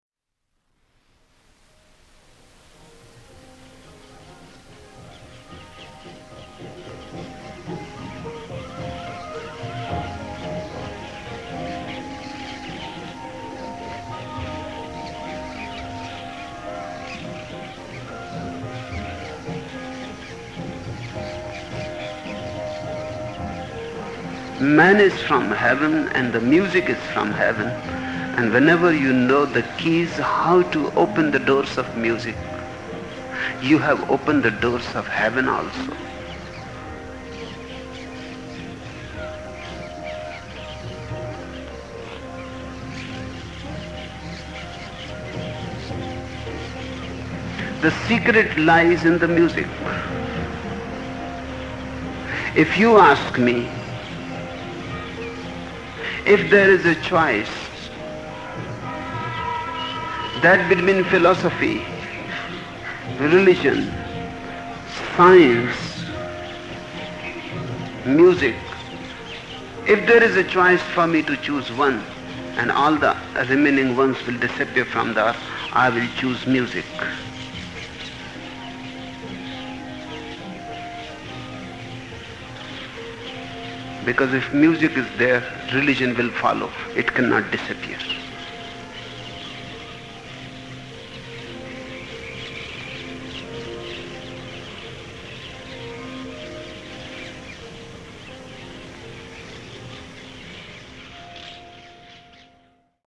(Osho, Tao: The Three Treasures Vol. 2 #10) Note: Listen to this text, accompanied by music (1:43 min., 128 Kbit/s, 1.6 MB, a beautiful intro to a sufi dance tape from Pune 1) Read also: Osho on Jazz Dynamic Meditation and Rock Music